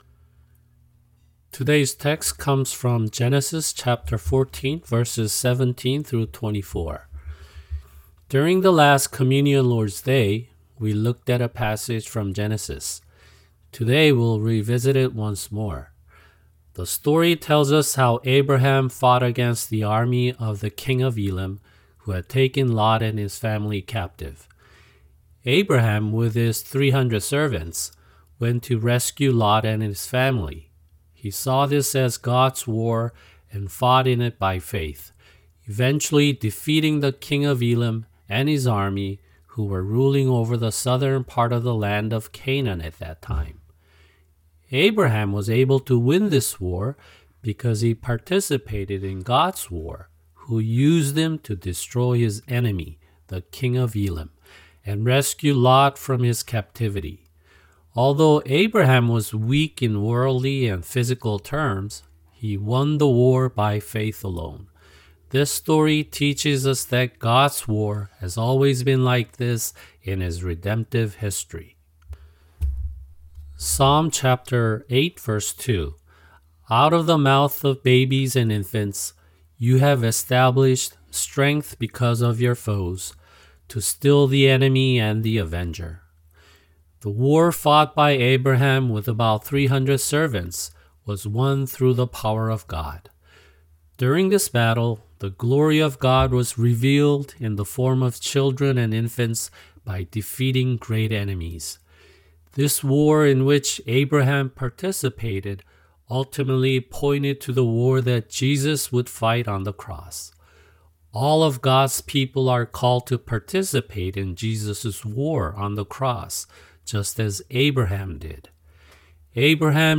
[주일 설교] 창세기 14:17-24(2)